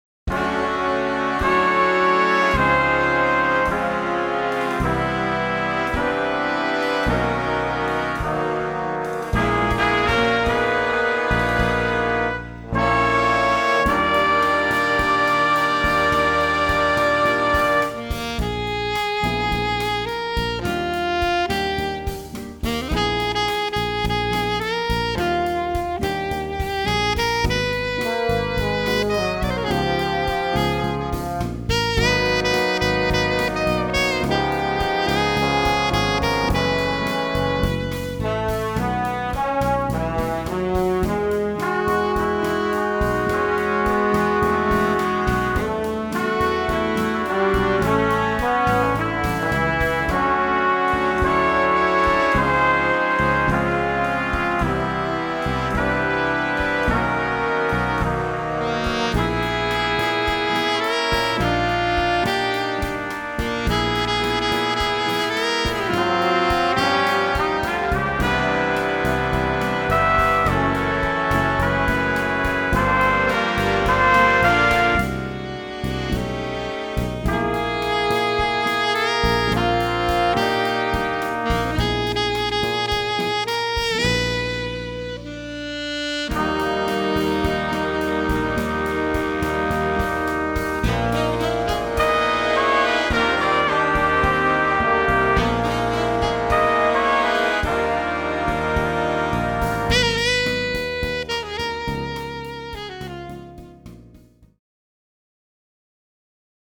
Voicing: Alto Saxophone w/BB